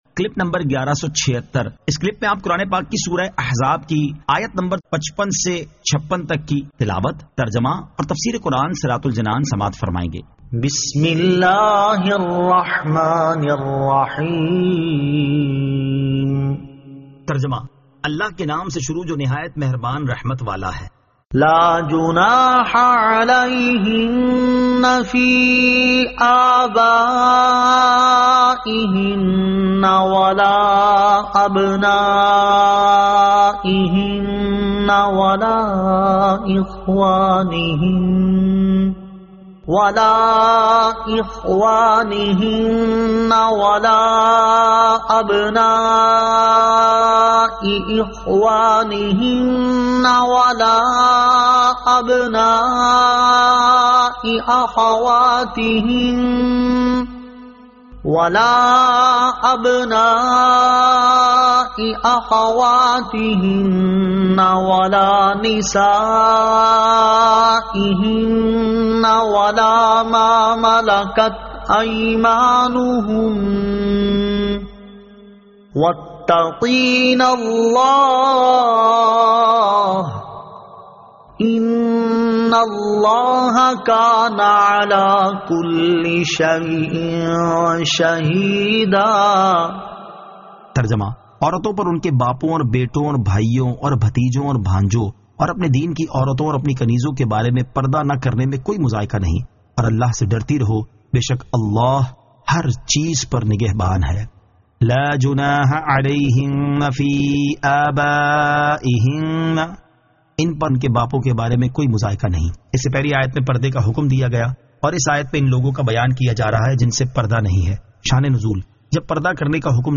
Surah Al-Ahzab 55 To 56 Tilawat , Tarjama , Tafseer